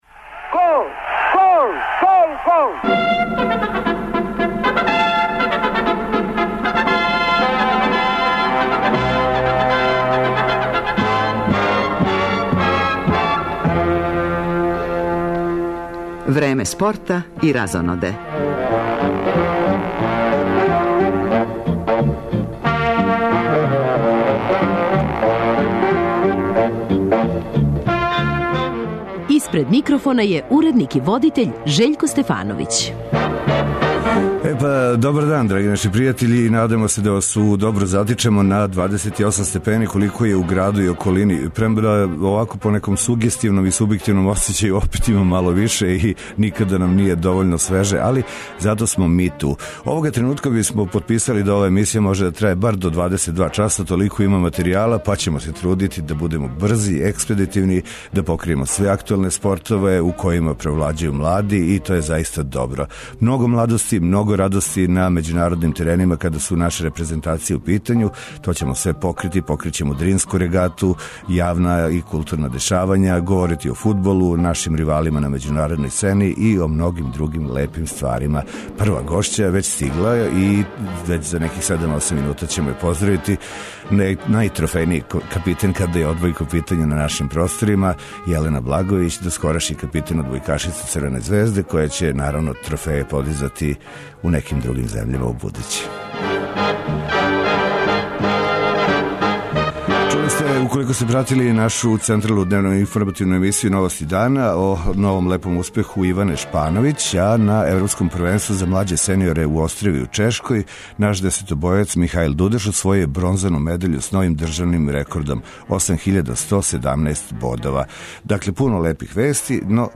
Говоримо и о ривалима наших клубова на међународној фудбалској сцени, уз коментаре званичника Партизана, Звезде, Војводине и Рада.